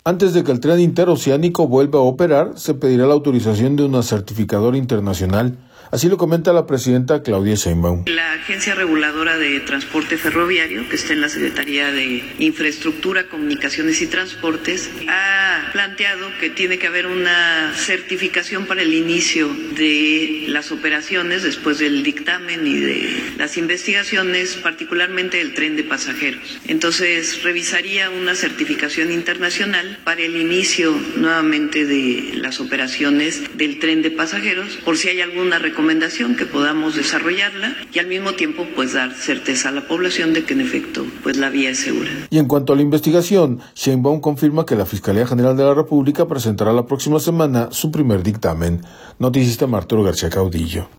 Antes de que el Tren Interoceánico vuelva a operar se pedirá la autorización de una certificadora internacional, así lo comenta la presidenta Claudia Sheinbaum.